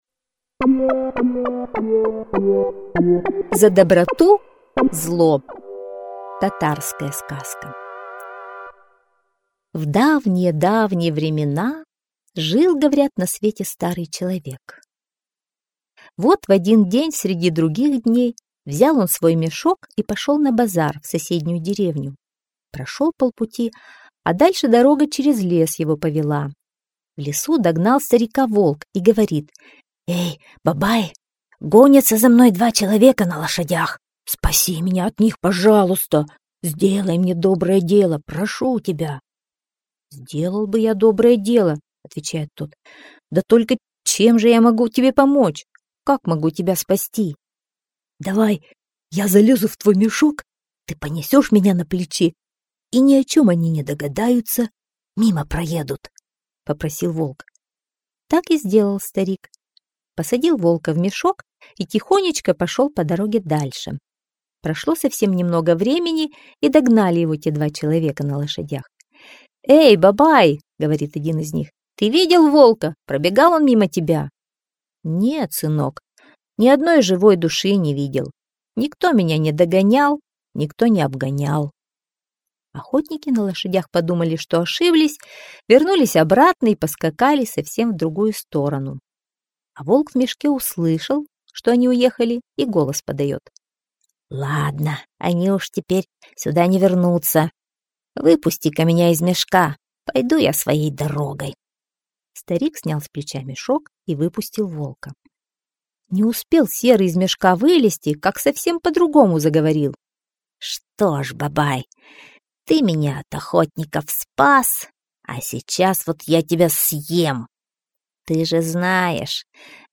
За доброту-зло - татарская аудиосказка - слушать онлайн